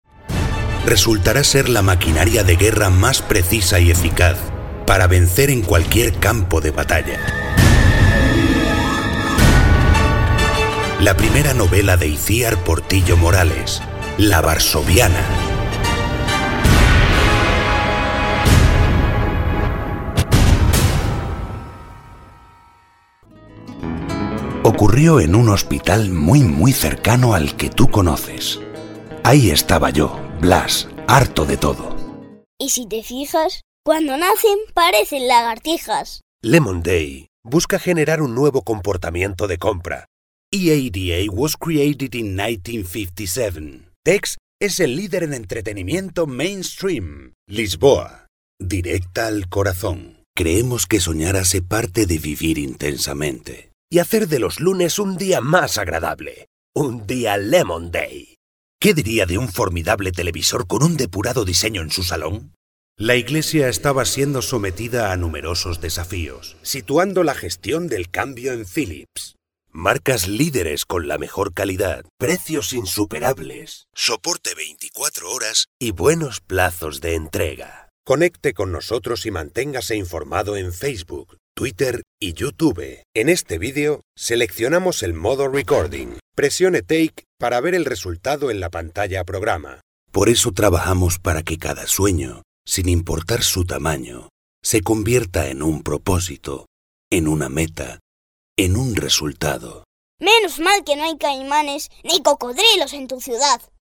Sprechprobe: Sonstiges (Muttersprache):
Experienced Spanish home studio voiceover talent and producer.